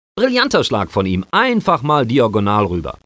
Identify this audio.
Kommentator: